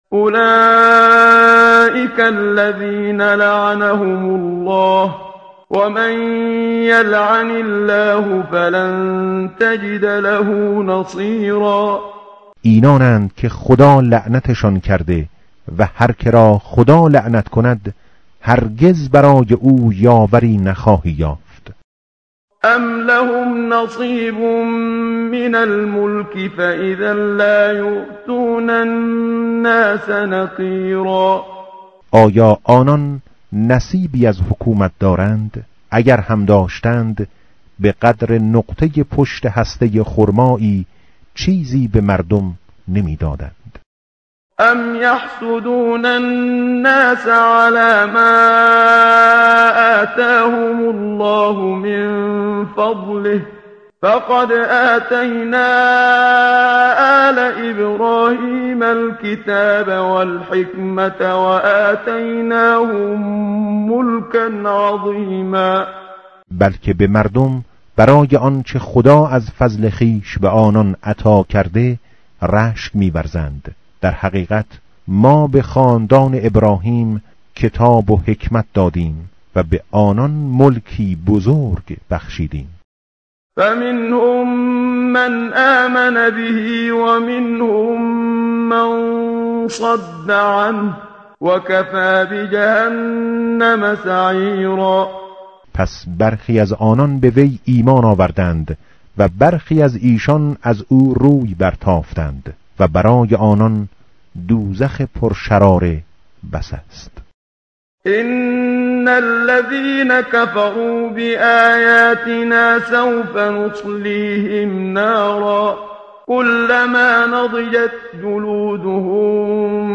tartil_menshavi va tarjome_Page_087.mp3